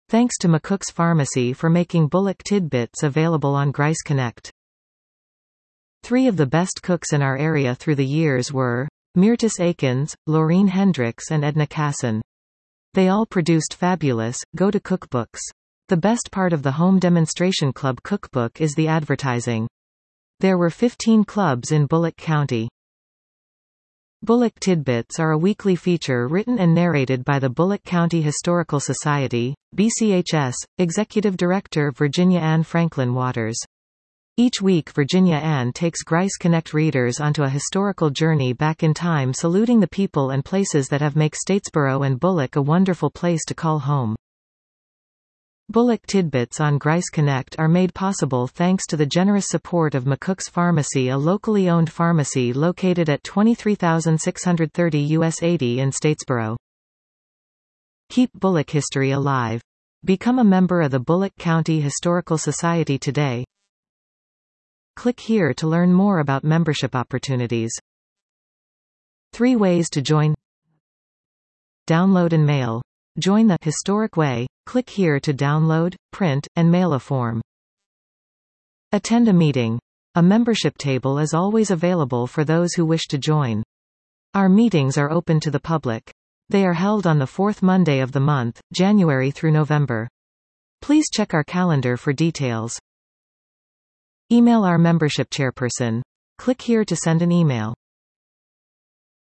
Bulloch Tidbits are a weekly feature written and narrated by the Bulloch County Historical Society